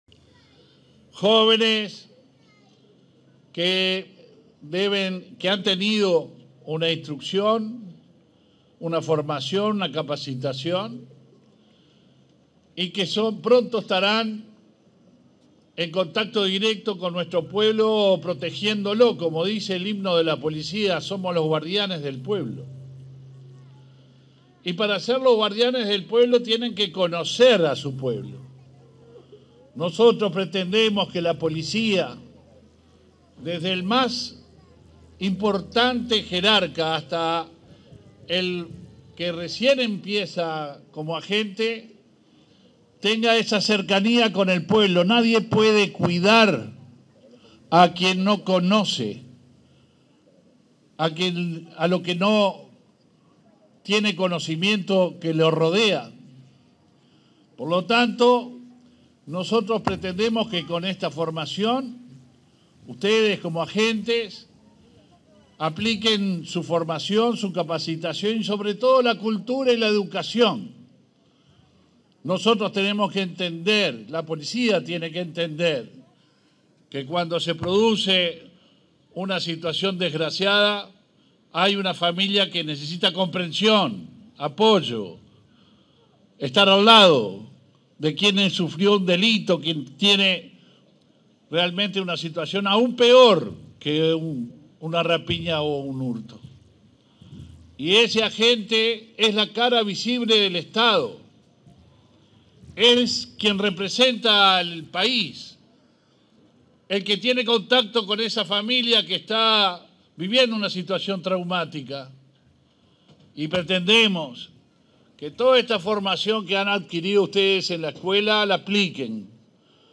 En el marco de la ceremonia de egreso de agentes de policía en Canelones, este 31 de marzo, se expresó el ministro del Interior, Luis Alberto Heber.